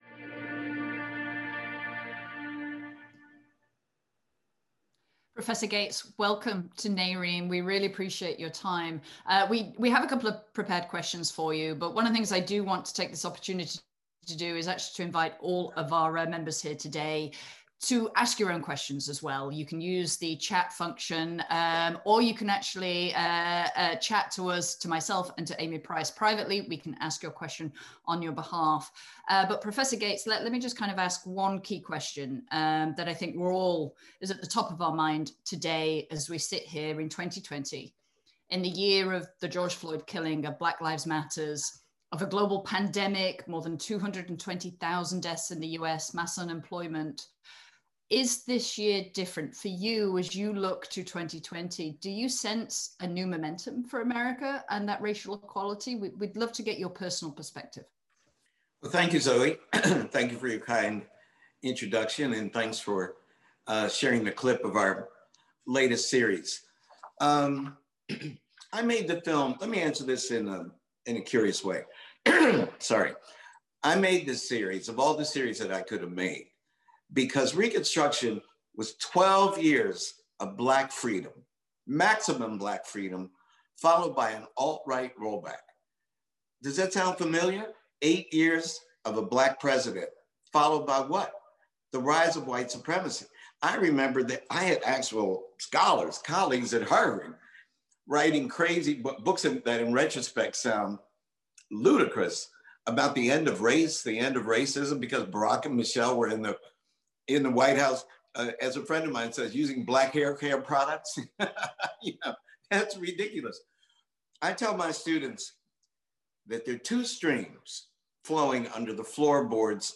Professor Henry Louis Gates, Emmy-award winning filmmaker, literary critic, historian, scholar and institution builder Listen to the discussion with Prof Henry Louis Gates during the 2020 Executive Officer meeting as he speaks about the need to recognize the history of racial inequality in the U.S. to help us move forward in the future. Calling for transformative change in public education, financial literacy and affirmative action, Prof Gates said action started with targets and goals at corporations to include more diversity and ethnicity in leadership positions - and the need to work even harder on retention, by mentoring people of color and women.